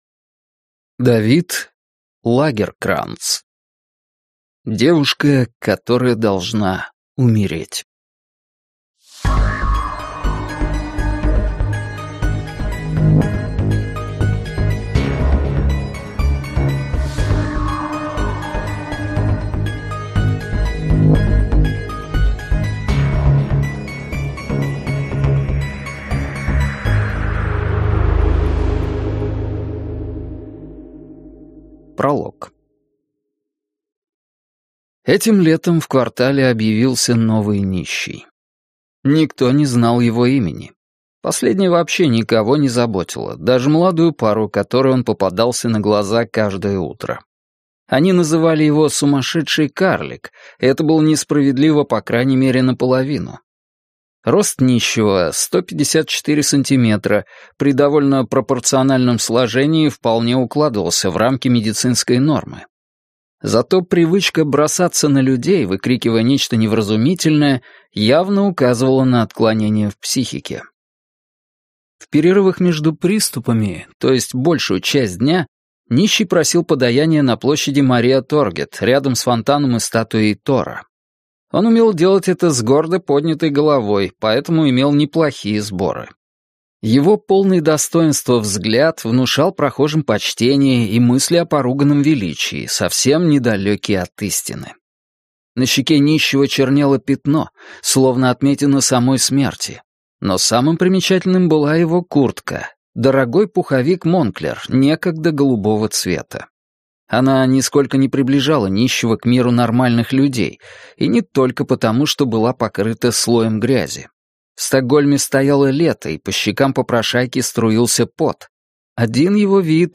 Аудиокнига Девушка, которая должна умереть - купить, скачать и слушать онлайн | КнигоПоиск